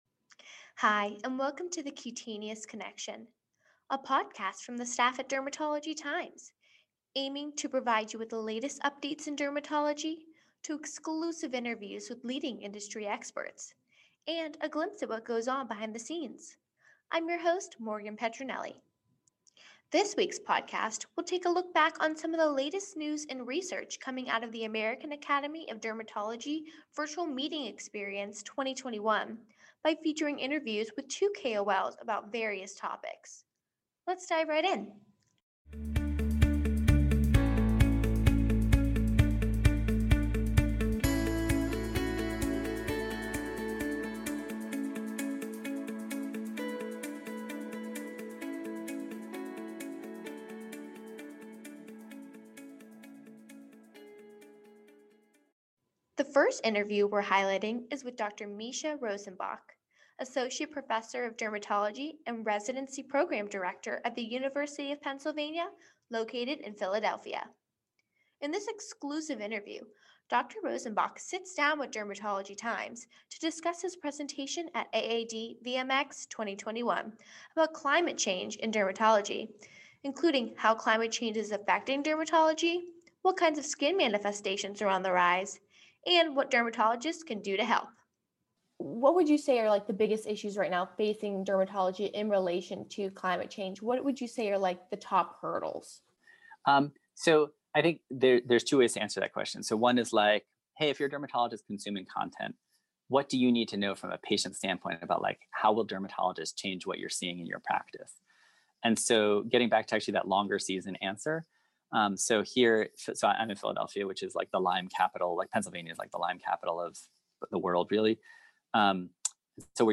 Headliner Embed Embed code See more options Share Facebook X Subscribe In this episode, we take a look back at what happened at the American Academy of Dermatology Virtual Meeting Experience 2021 (AAD VMX 2021), including exclusive interviews with two KOLs about their presentations regarding climate change and new data on biologics for psoriasis.